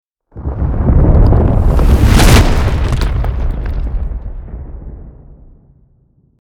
Download Cinematic sound effect for free.
Cinematic